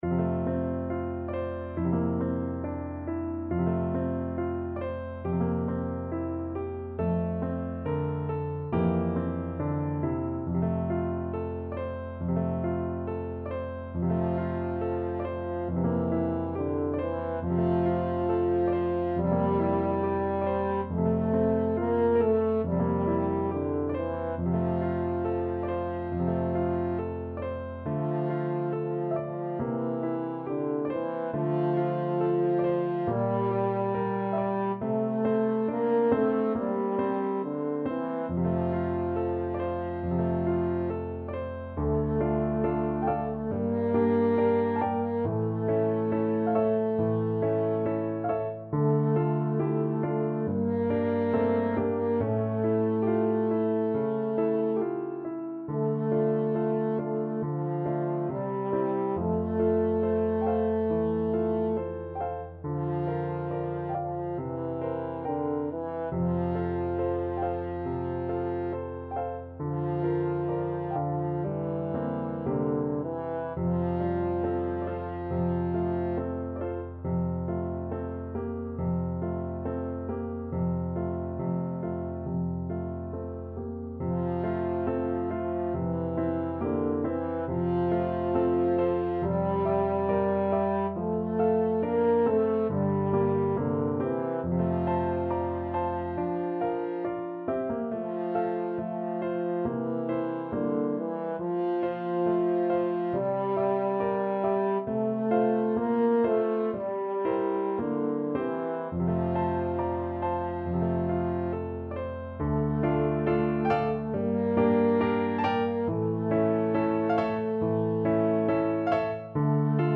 French Horn
Traditional Music of unknown author.
~ = 69 Andante tranquillo
F major (Sounding Pitch) C major (French Horn in F) (View more F major Music for French Horn )